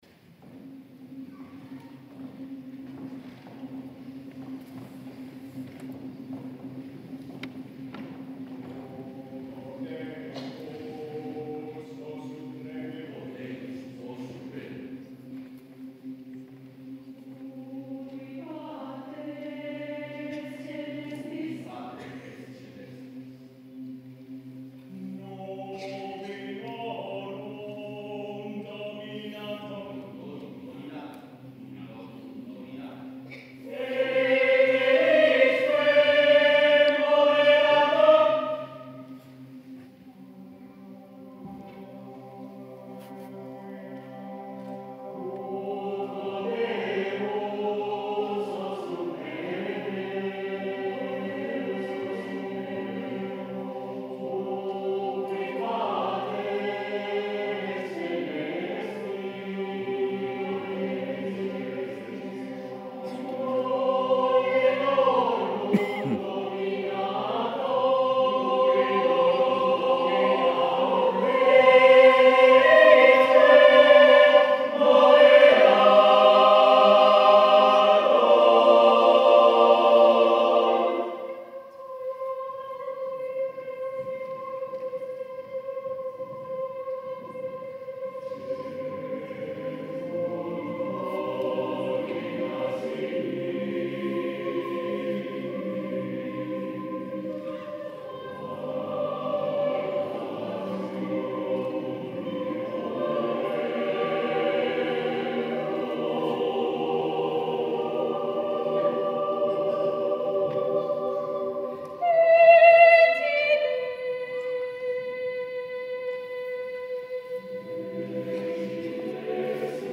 • Plantilla: SATB divisi + percusión opcional